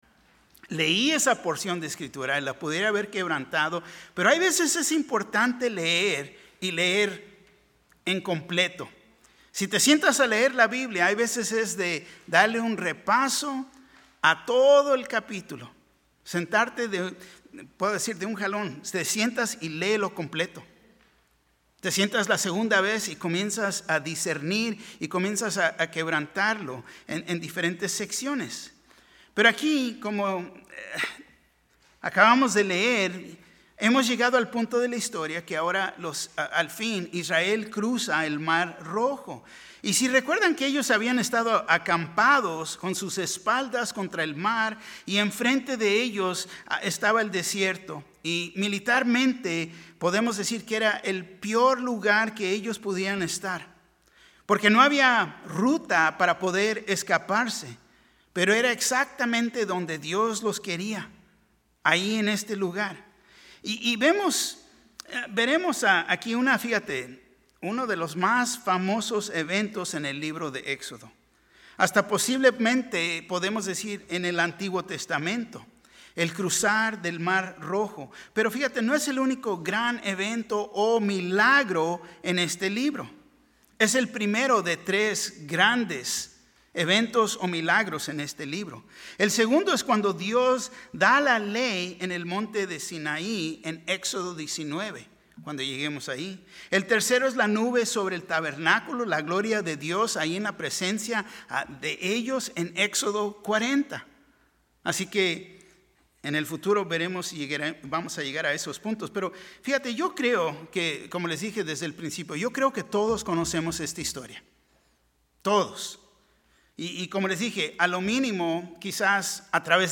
Un mensaje de la serie "Liberados."